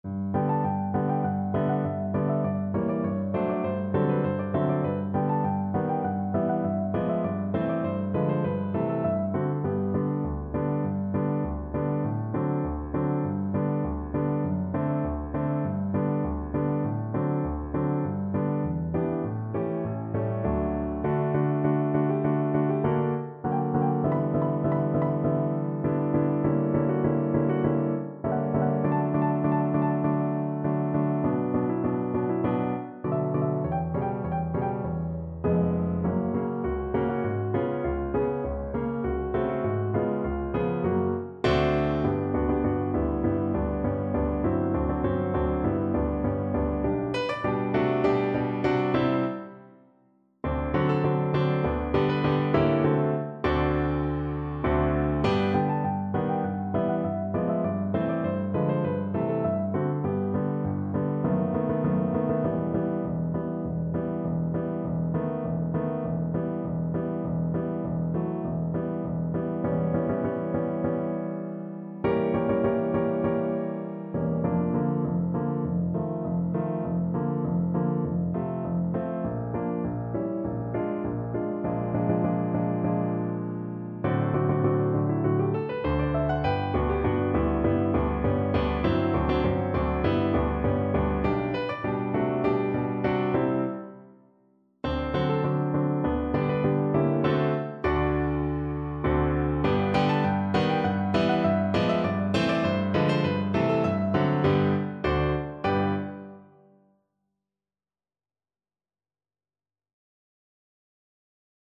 Cello
2/4 (View more 2/4 Music)
G major (Sounding Pitch) (View more G major Music for Cello )
Allegretto grazioso
Classical (View more Classical Cello Music)